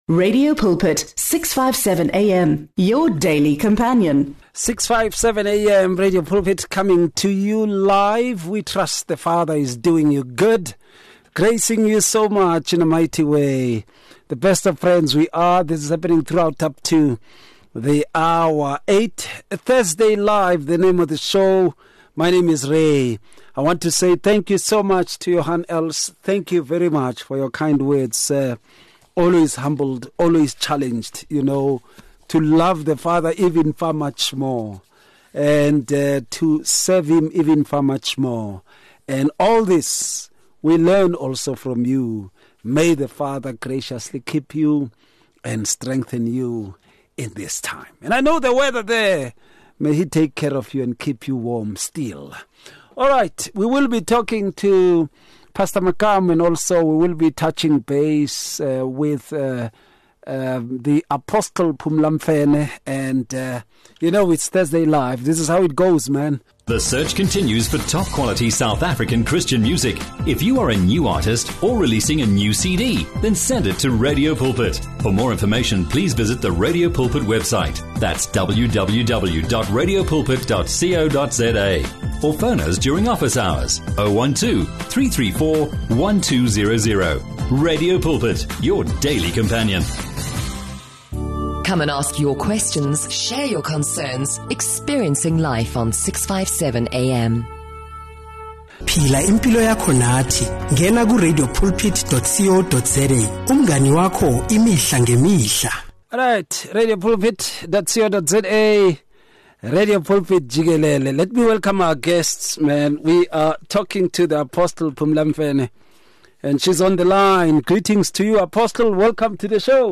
They delve into the consequences of neglecting key spiritual responsibilities such as showing mercy, fulfilling ministerial duties, and serving others. The discussion highlights how neglect in these areas weakens both individual faith and the broader church community.